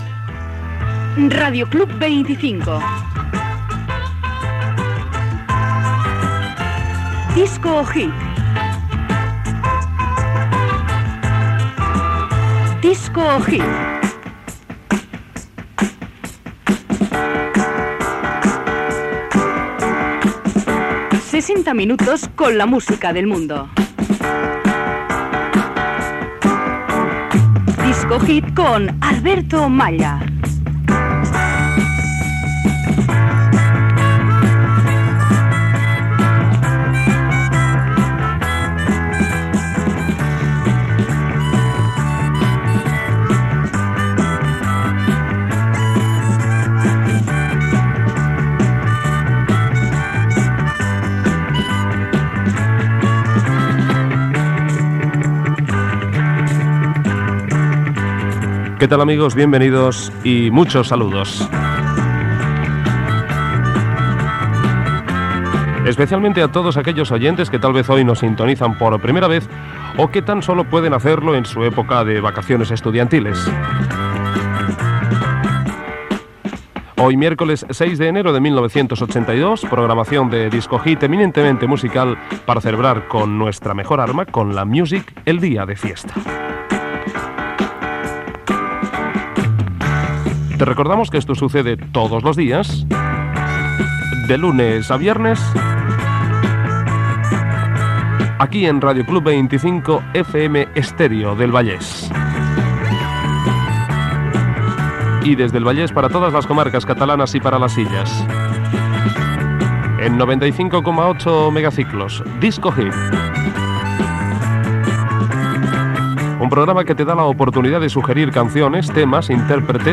Careta del programa, presentació del programa el dia de Reis, objectiu del programa, adreça de l'emissora i pas a un tema musical.
Musical